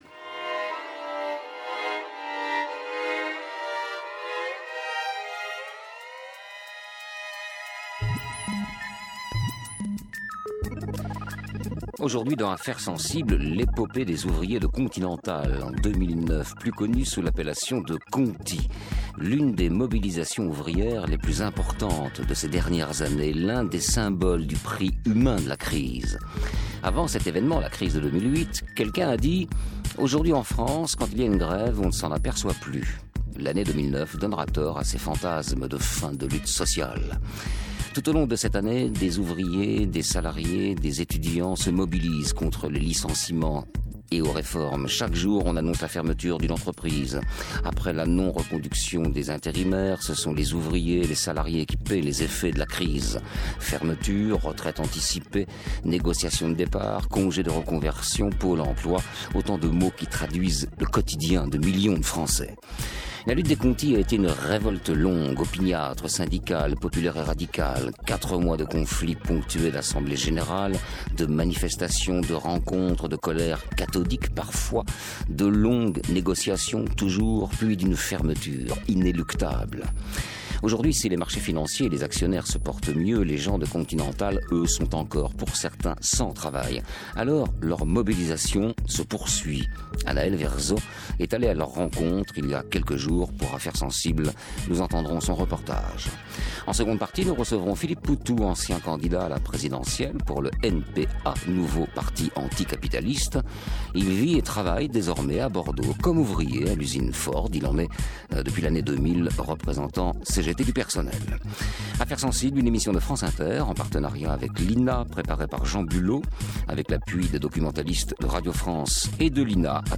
L’émission
Sommaire 2009 Reportage Entretien L’émission 2009 Tout au long de cette année, des ouvriers, des salariés, des étudiants se mobilisent face aux licenciements et aux réformes.
Entretien En seconde partie d’émission, nous recevrons Philippe Poutou , ancien candidat à la présidentielle pour le Nouveau Parti Anticapitaliste (NPA).